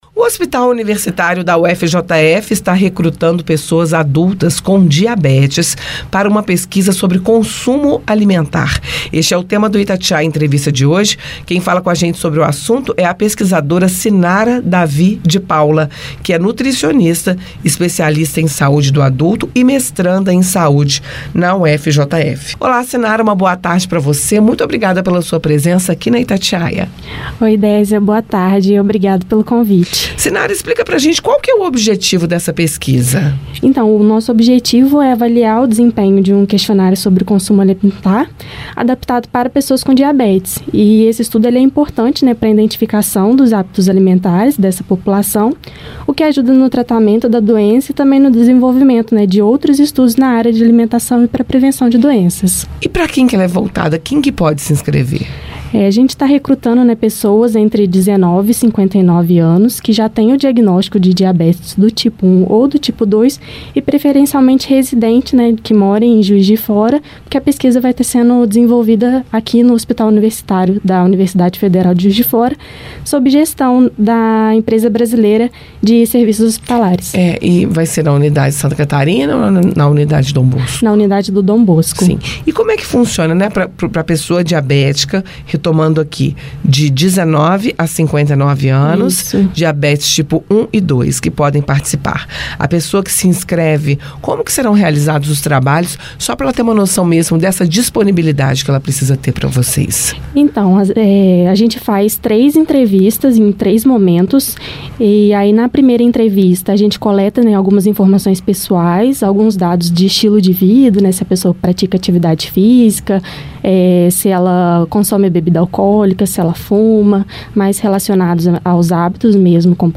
A alimentação é um fator de risco.”, comenta ela durante a participação no Itatiaia Entrevista.